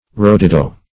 Rowdydow \Row"dy*dow\, n. Hubbub; uproar.